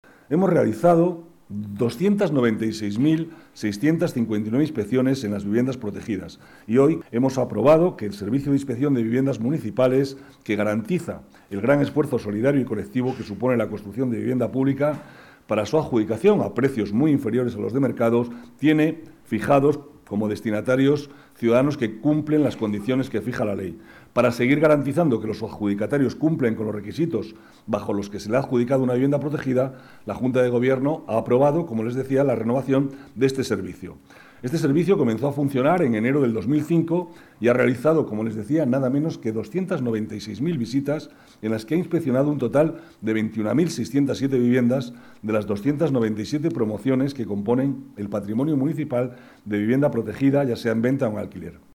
Nueva ventana:Declaraciones del vicealcalde, Manuel Cobo, sobre las inspecciones de vivienda protegida